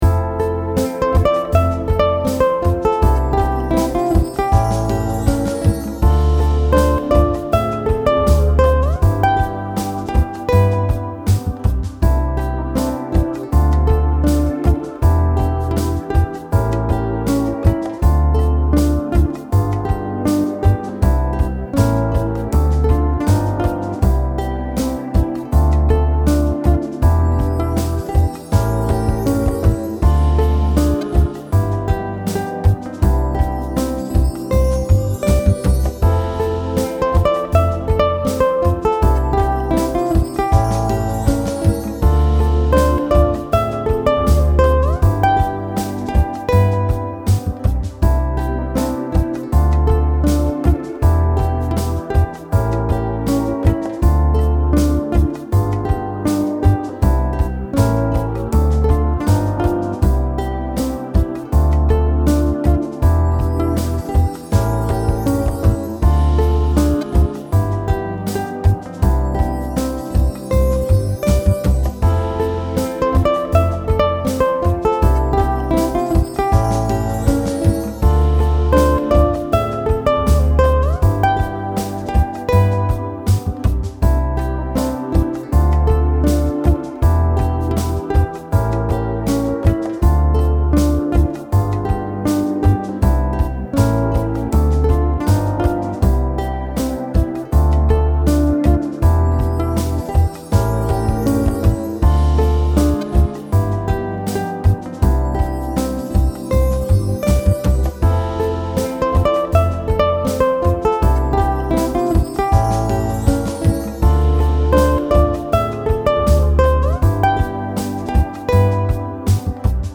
Mithilfe eines Playbacks zur Begleitung, das ich
Mary-Had-a-Little-Lamb-UkulelenPlayback.mp3